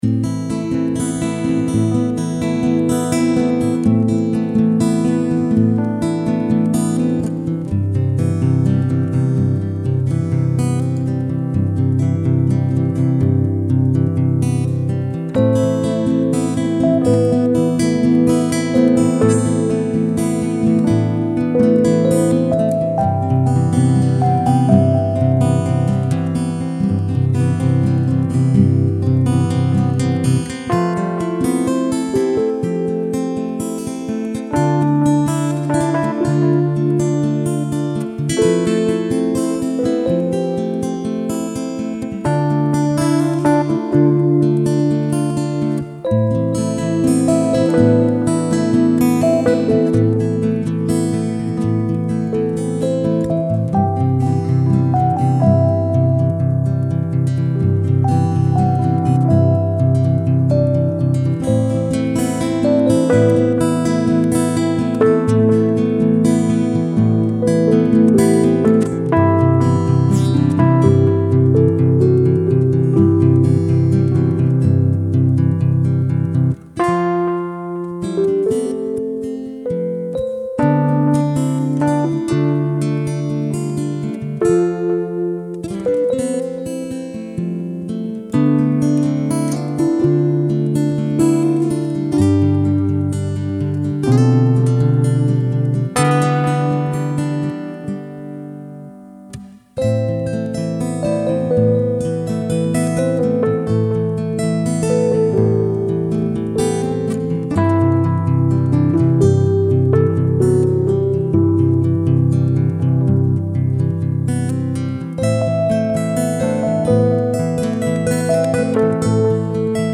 Una canción sencilla grabada el domingo por la tarde. Tiempos e instrumentos muy mejorables.
Y una versión que intenta imitar el sonido Rhodes: